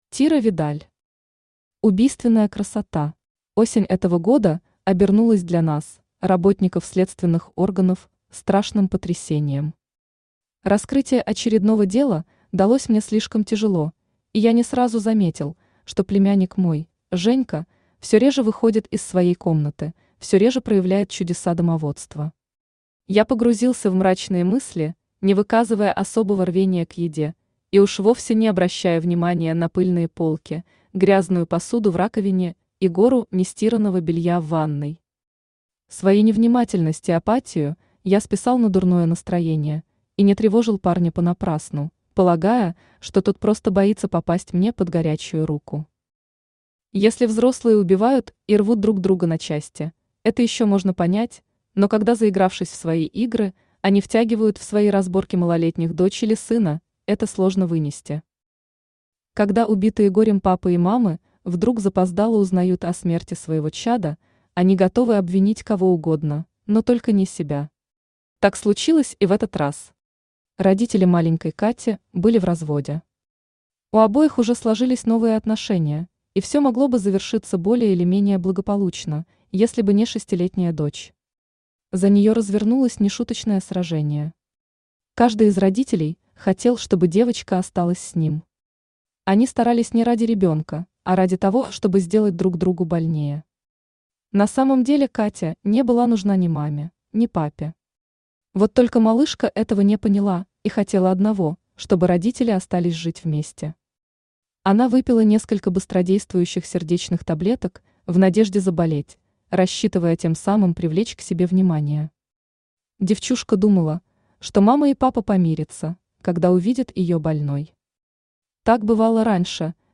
Аудиокнига Убийственная красота | Библиотека аудиокниг
Aудиокнига Убийственная красота Автор Тира Видаль Читает аудиокнигу Авточтец ЛитРес.